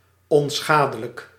Ääntäminen
US GenAm: IPA : /ˈhɑɹmləs/ RP : IPA : /ˈhɑːmləs/